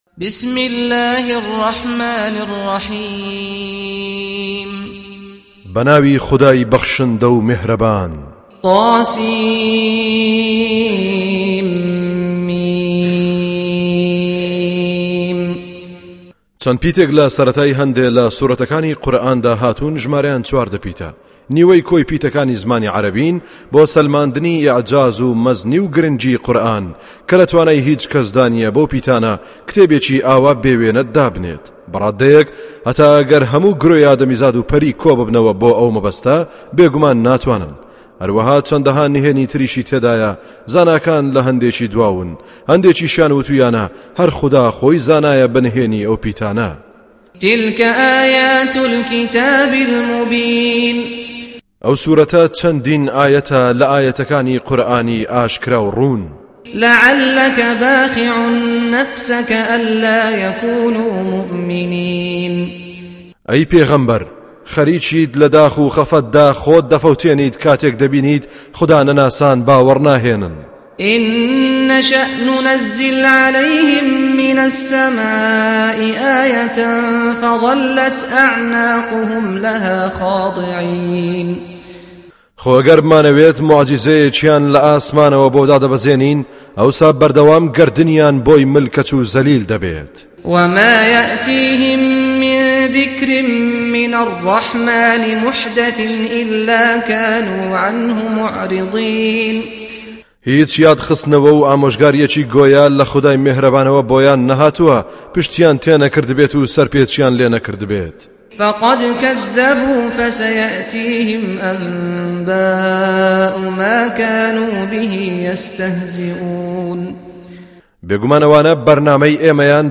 Recitation
Home Of Kurdish Translation With Qari SaadAl-Ghamdi